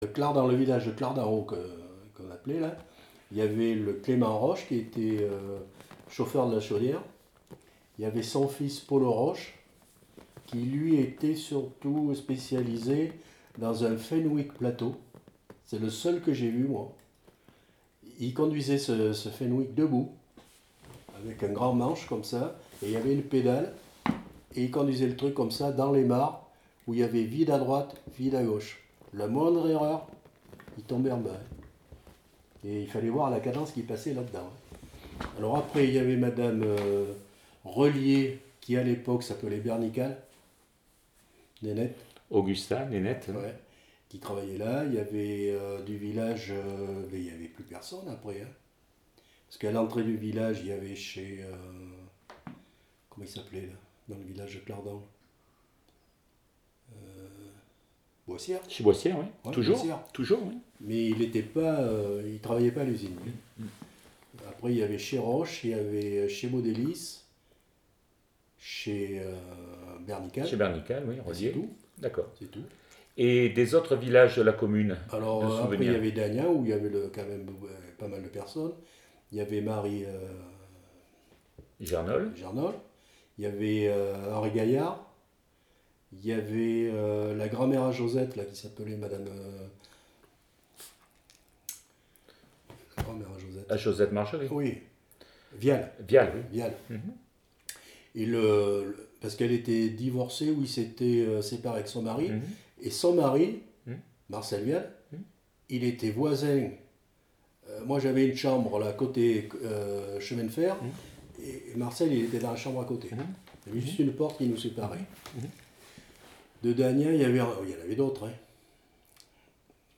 Mémoire de l'usine Labro, interview